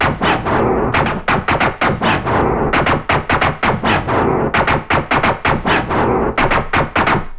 MOOG_SCRATCHES_0001.wav